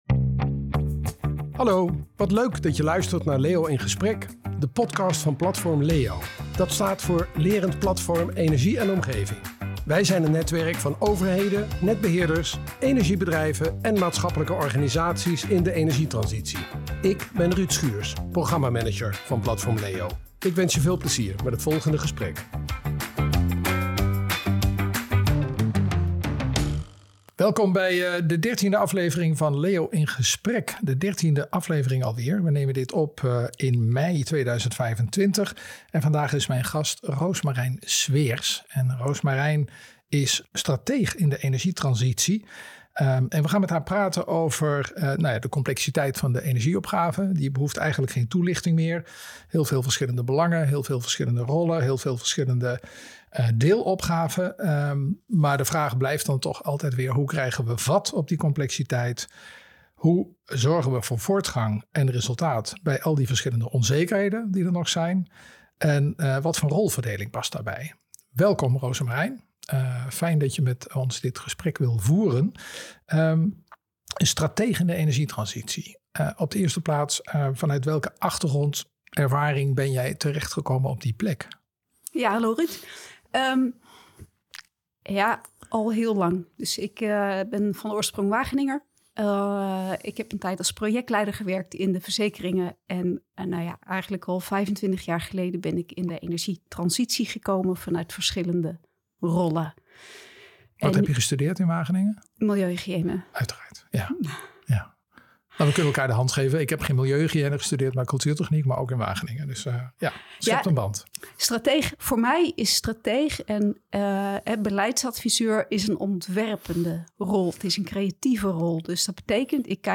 LEO in Gesprek is een reeks interviews met auteurs, opinieleiders, bestuurders en onderzoekers op het snijvlak van omgevingsmanagement en energietransitie.